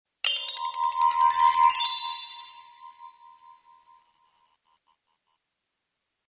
Необычный звук волшебной палочки (новый)